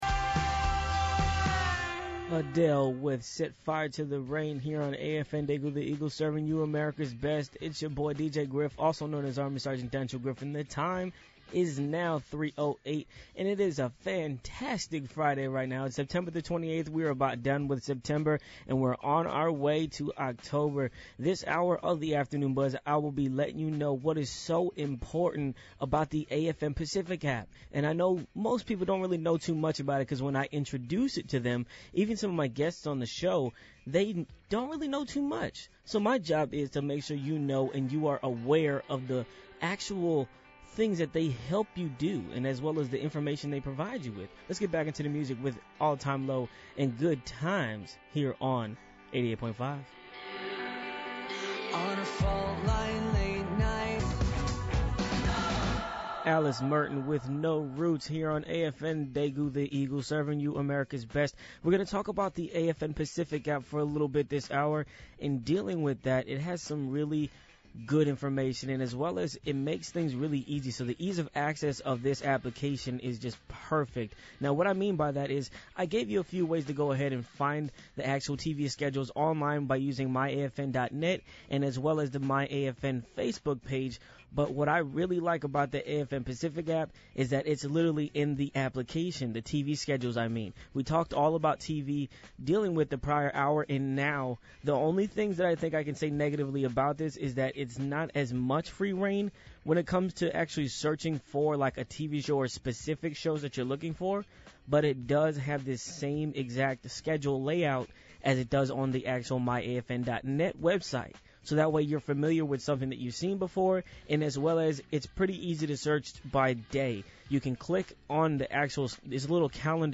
radio AFN show